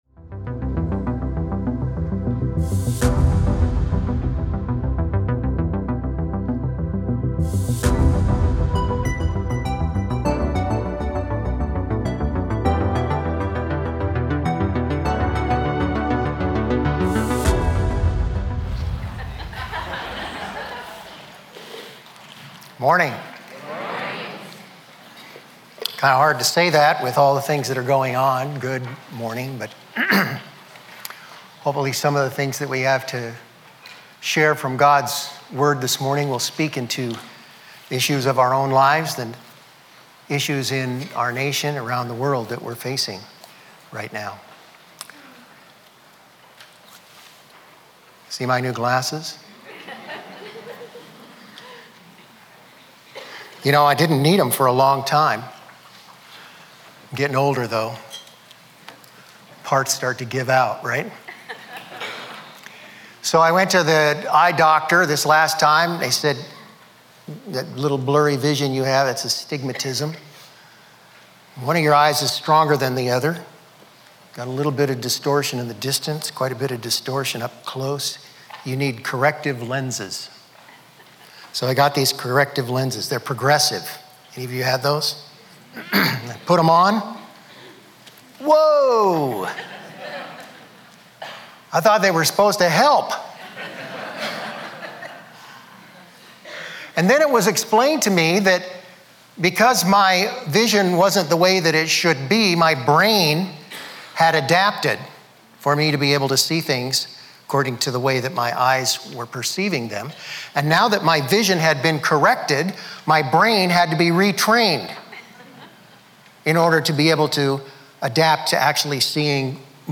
A message from the series "The Book of Acts."